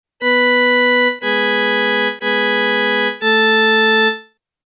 … to the musica ficta of sharpening fa, changing the major third to a minor third before the unison.